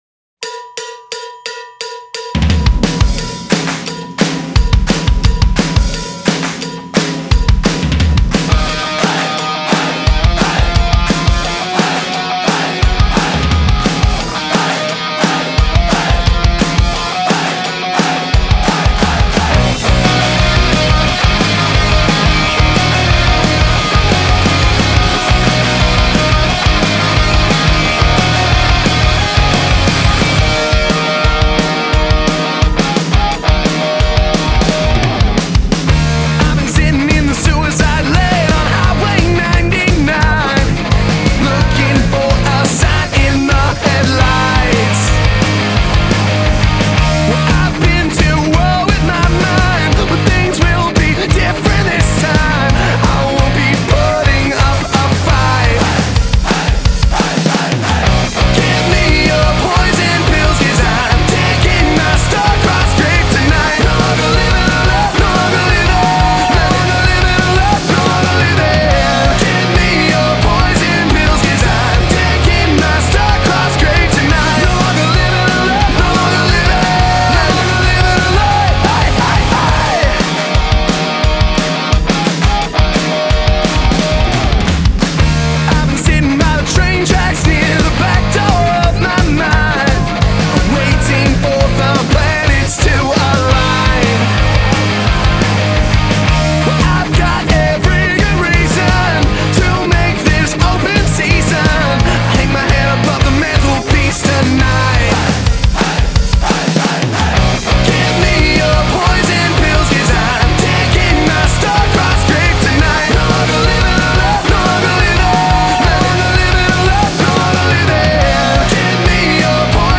Guitar/Piano/Background Vocals